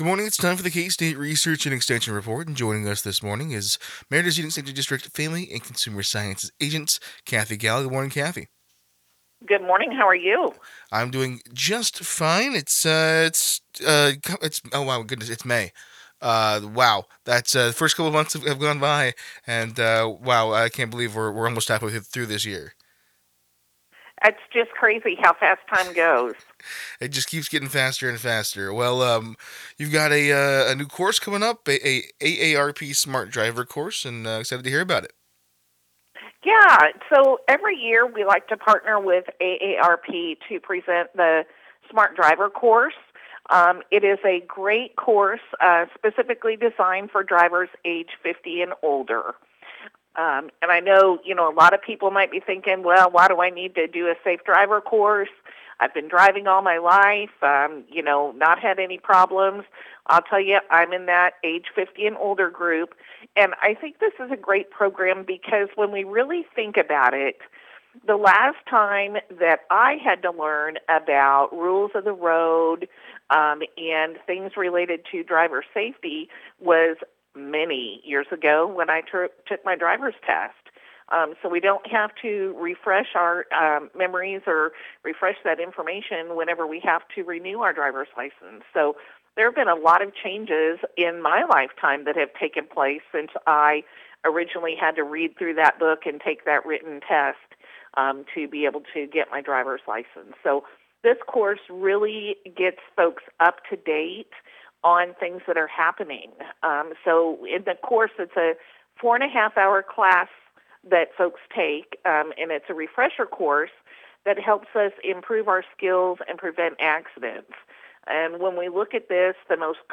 KOFO Interviews 2025